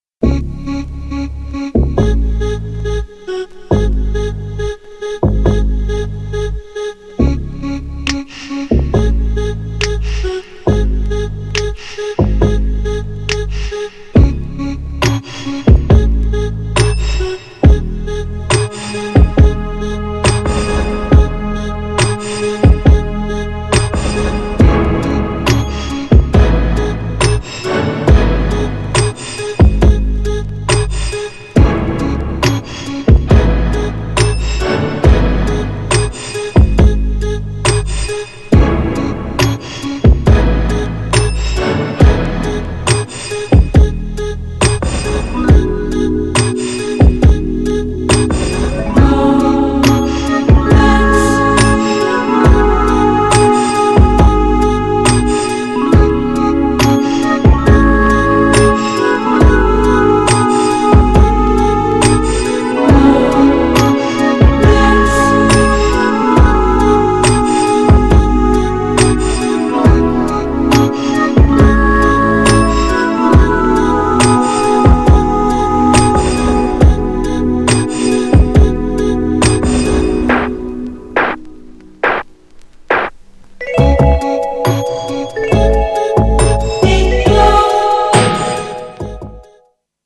GENRE Dance Classic
BPM 106〜110BPM